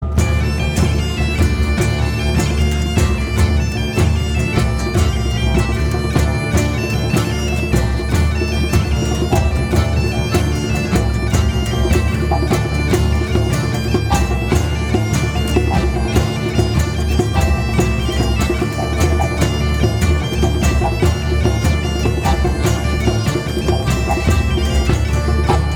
Этническая музыка